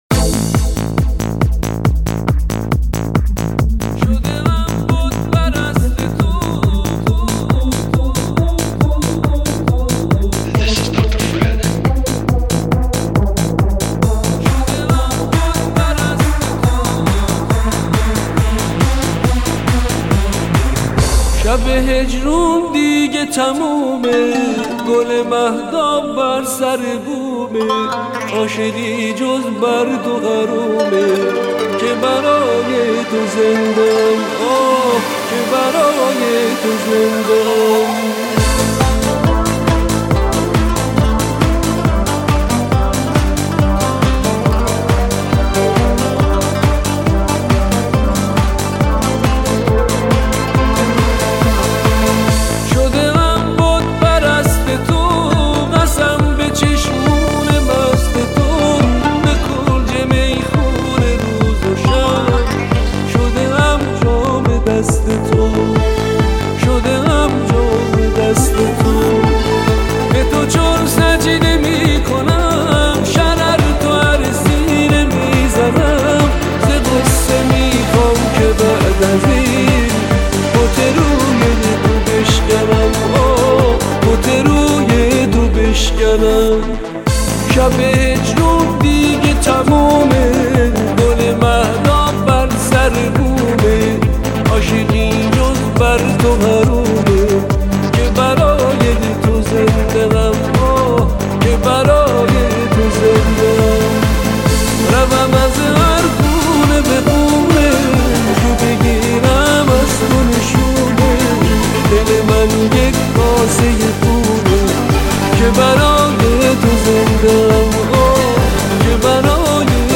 فوق العاده احساسی و دلنشین